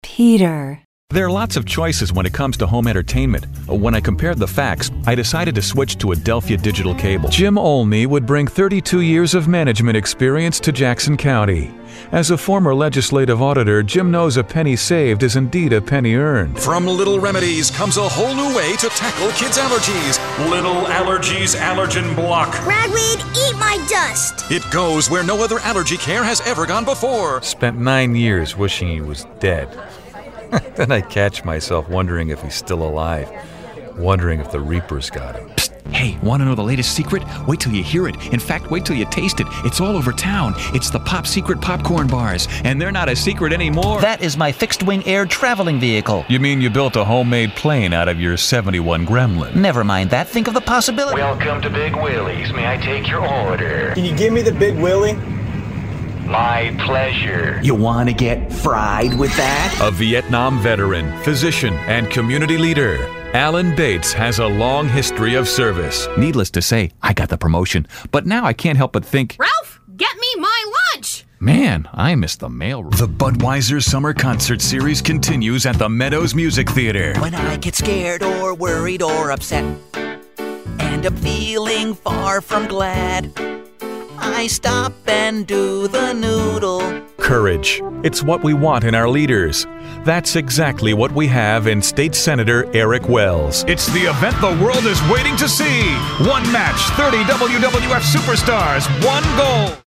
Showcase Demo
anti-announcer, Booming, bravado, cocky, confident, Deep Voice, promo, southern
animated, Booming, cartoon, character, deadpan, Deep Voice, grouchy
conversational, cool, country, Deep Voice, folksy, genuine, homespun, mellow, storyteller